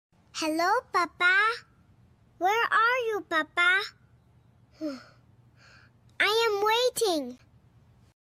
детский голос